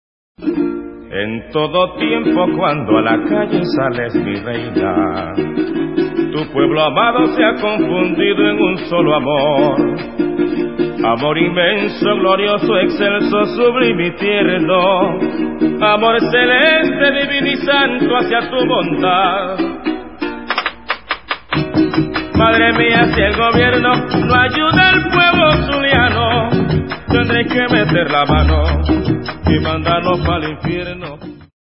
Gaita tradicional de Venezuela
Grabaciones Originales